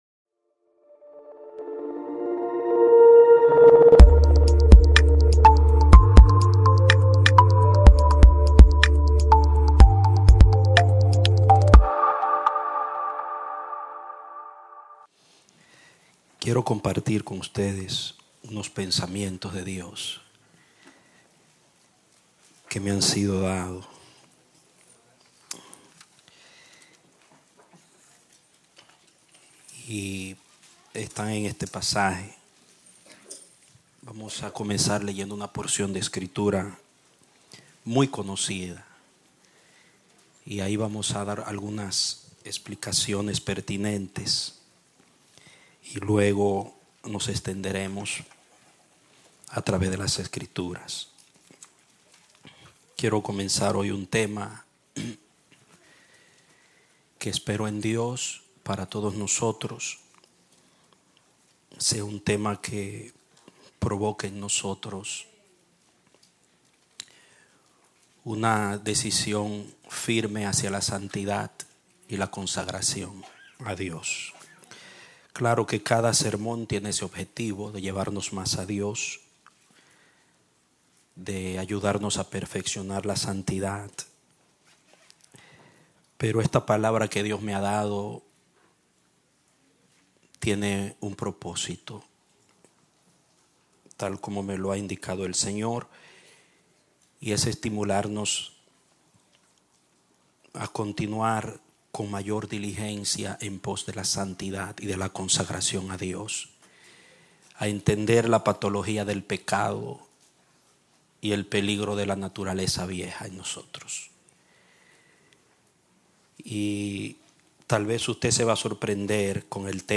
Un mensaje de la serie "Metástasis."